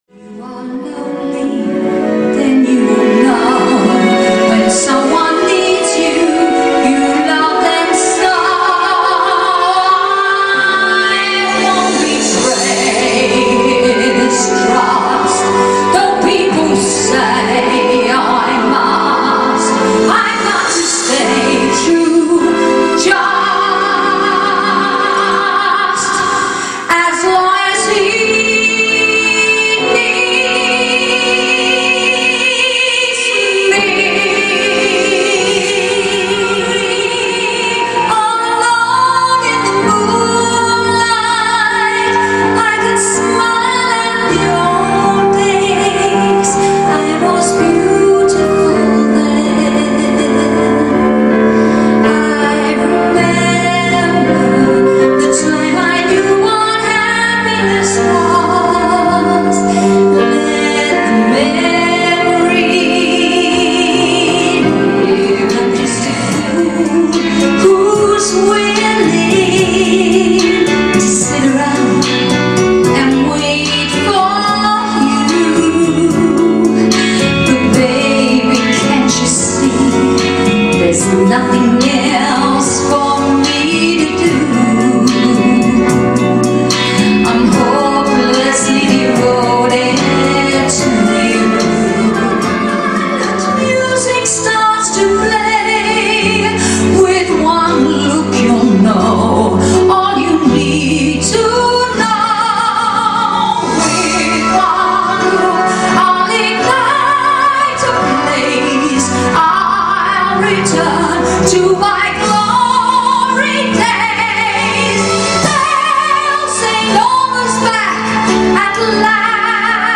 2 x female + 1 male artist.
Additional Vocalists can also be added.